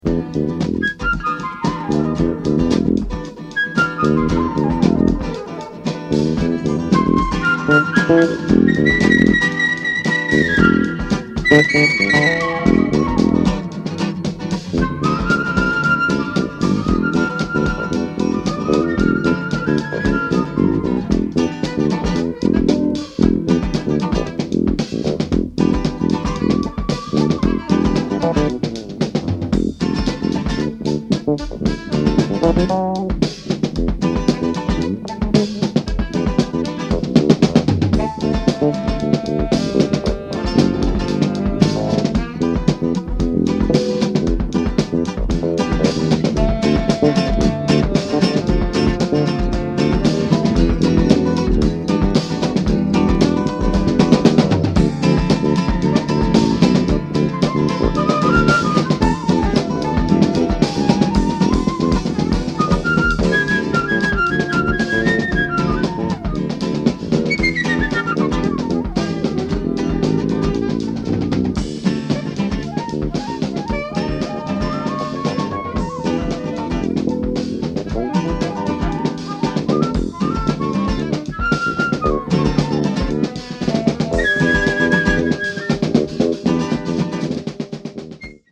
Rare private press, holy grail breaks LP from Holland!